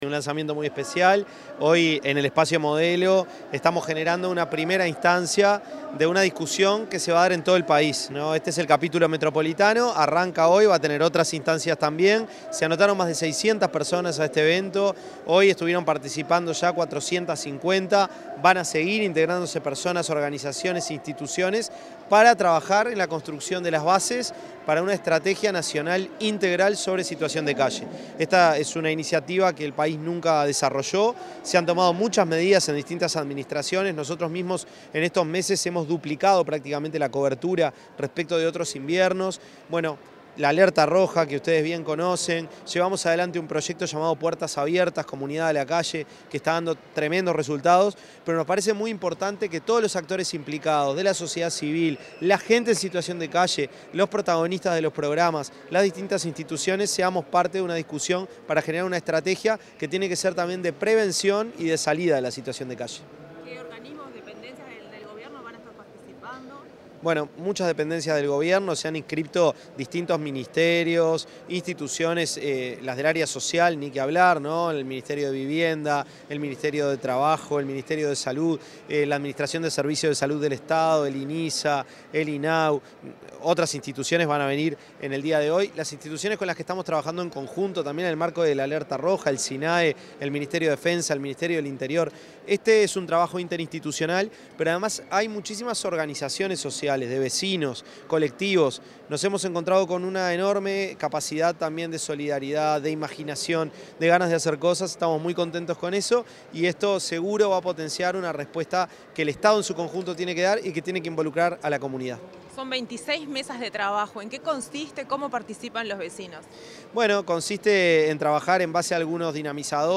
Declaraciones del ministro de Desarrollo Social, Gonzalo Civila
Declaraciones del ministro de Desarrollo Social, Gonzalo Civila 20/08/2025 Compartir Facebook X Copiar enlace WhatsApp LinkedIn Tras participar en el primer encuentro de coordinación para el abordaje de situaciones de calle, el ministro de Desarrollo Social, Gonzalo Civila, dialogó con la prensa.